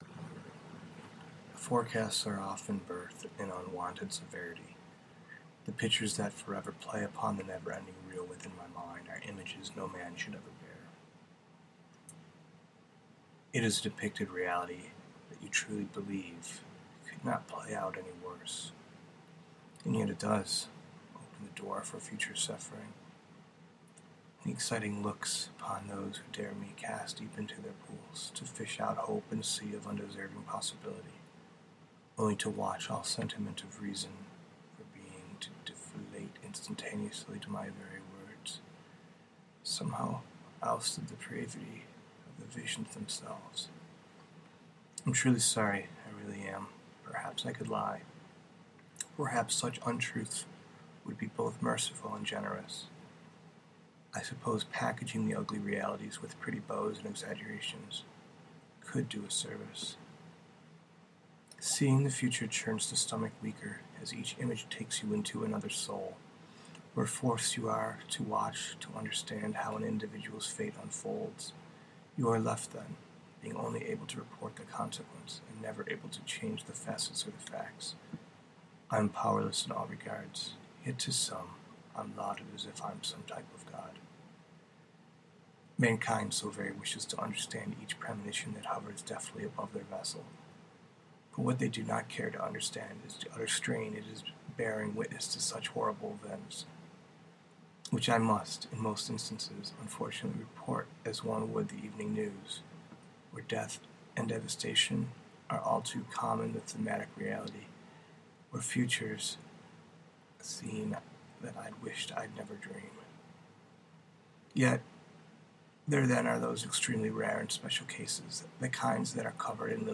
Recording, Mythology, metaphor, poetry, prose poetry, reading